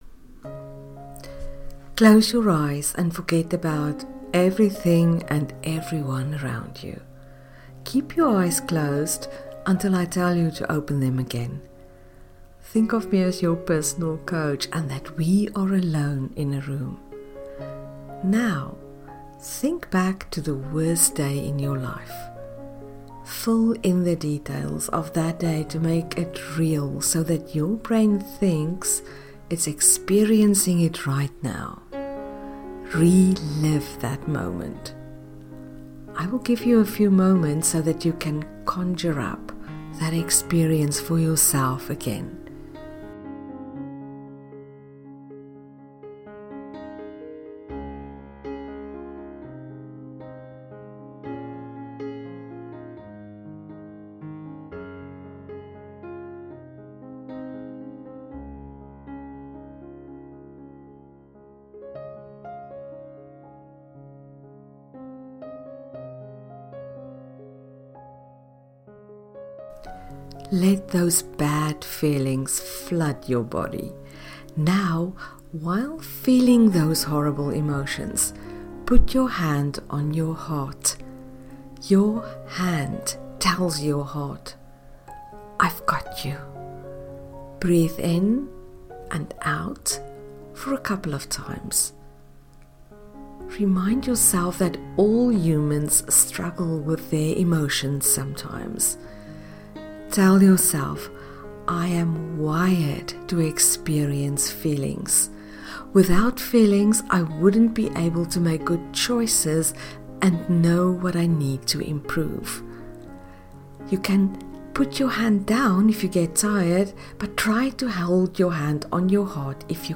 Lesson-6-Guided-Meditation.mp3